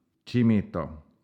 Kimito (Swedish: [ˈtɕimito]